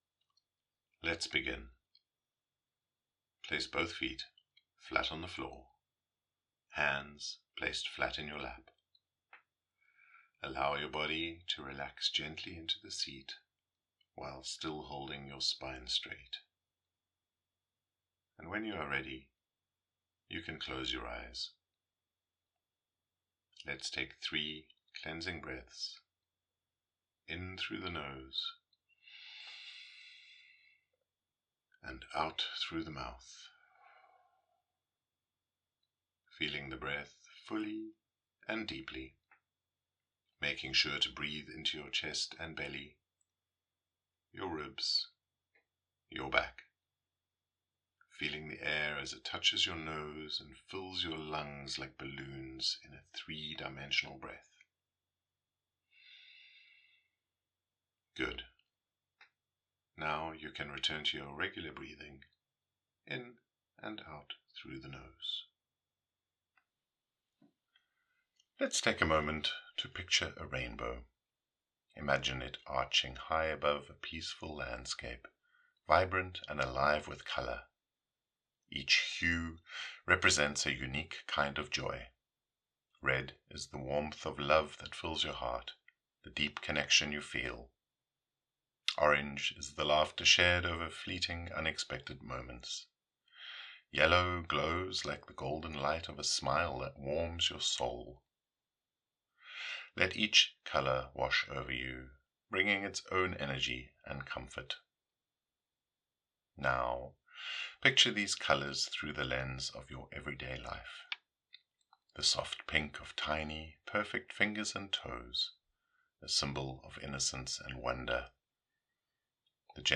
Workshop Meditation
MT01-meditation-Rope-Rainbow.mp3